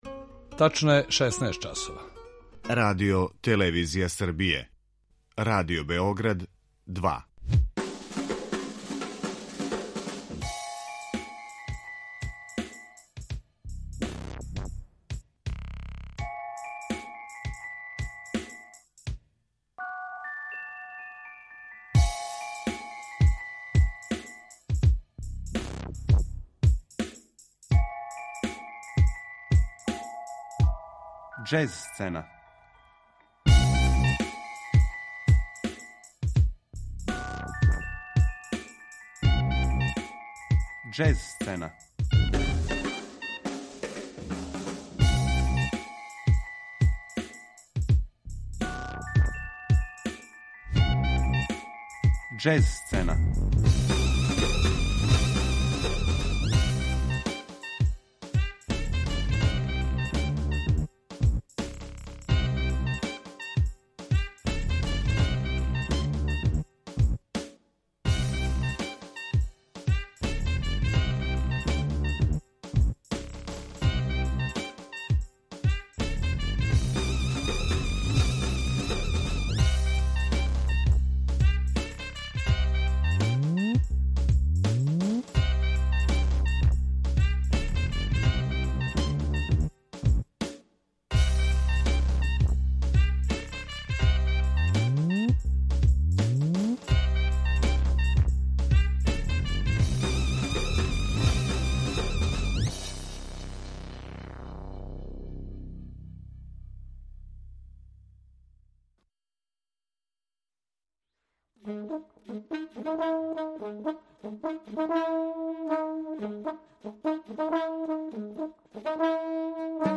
Уметнички портрет
Тим поводом емитујемо разноврсну музику из целокупне каријере овог уметника.